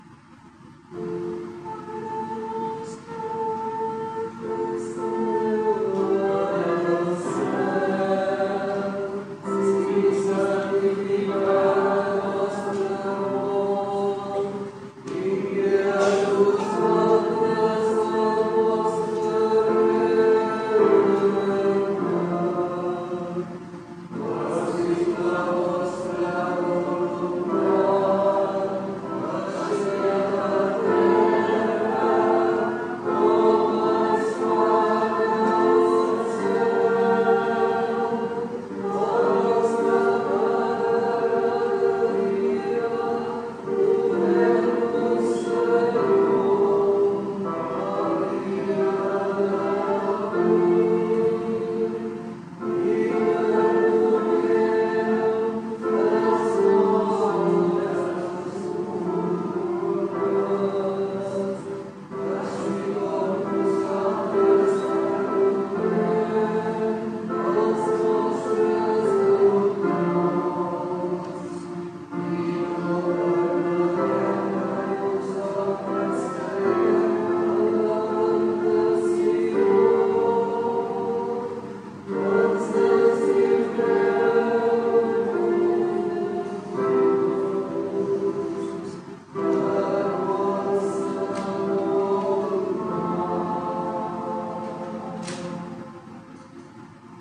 Pregària de Taizé
Parròquia de la Sagrada Família - Diumenge 28 de juny de 2015